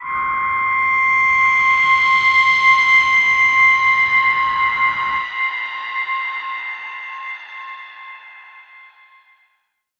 G_Crystal-C7-f.wav